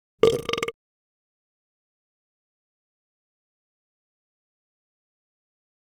petburp.wav